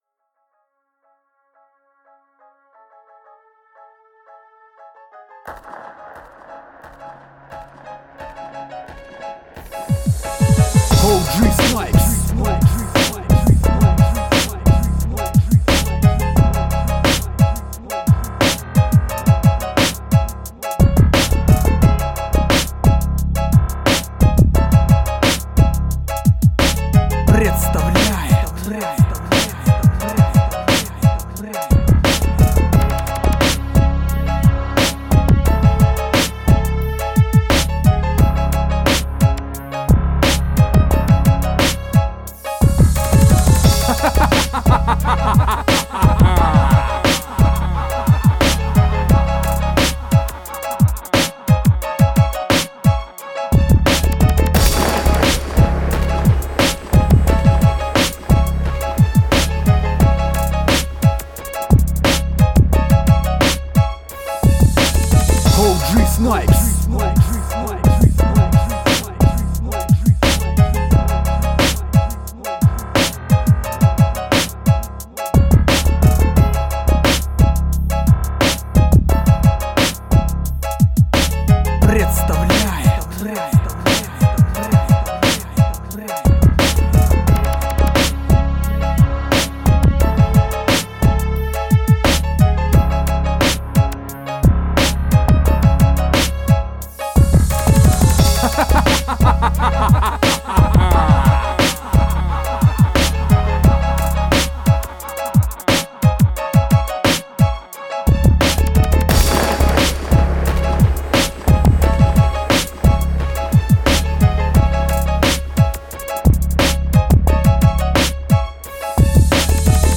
Gangsta Chicano Rap Beat